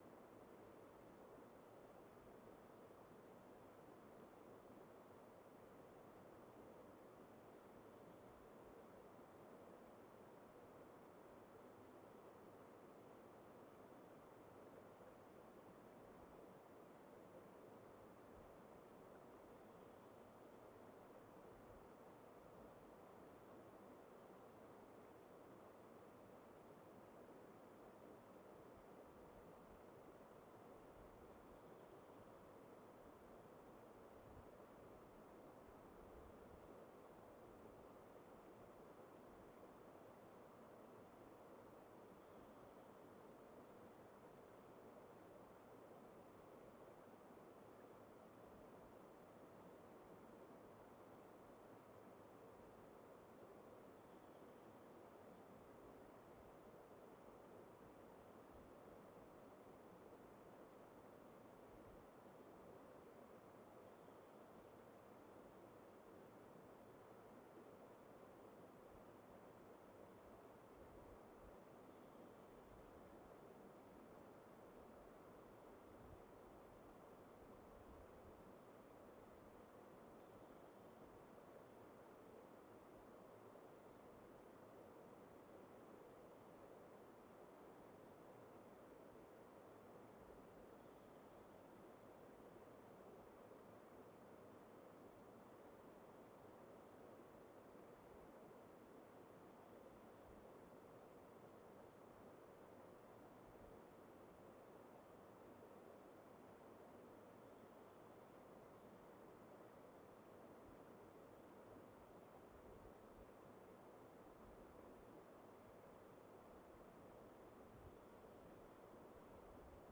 Quellrauschen63.mp3